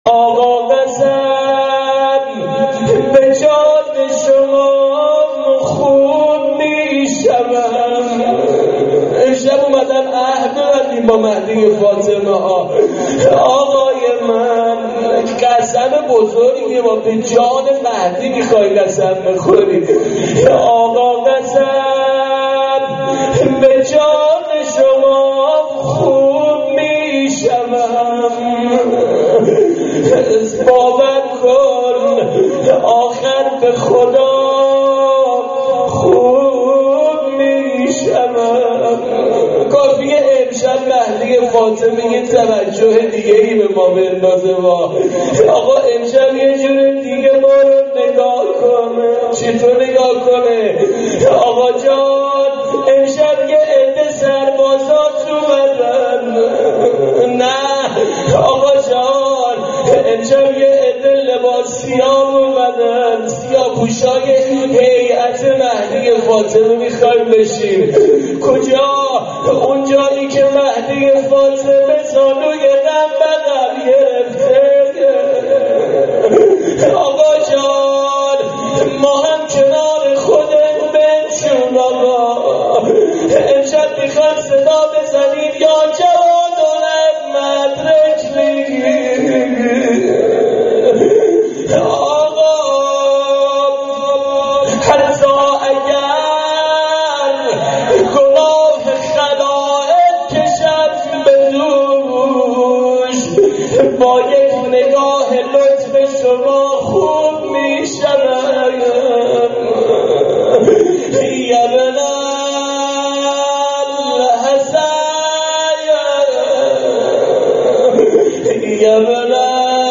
آقا قسم به جان شما خوب می شوم مناجات با امام زمان قبل از دعای توسل.mp3